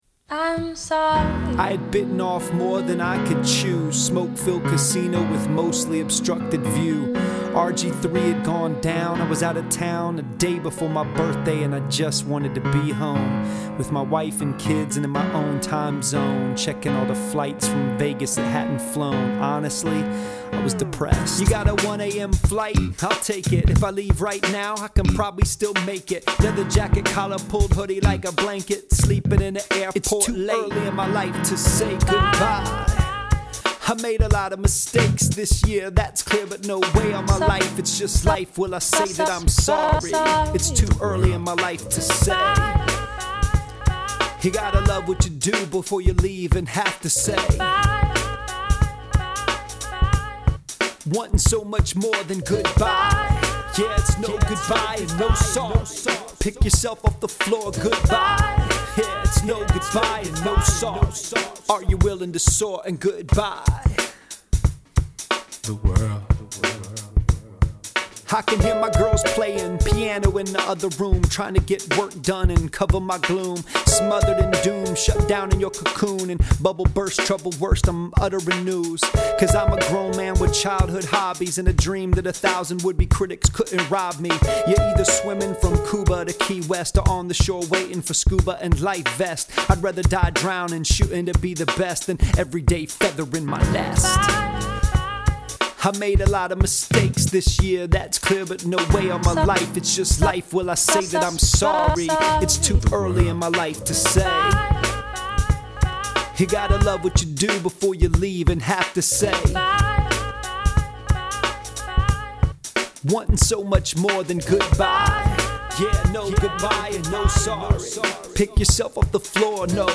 An Anniversary: Three Years of News Rap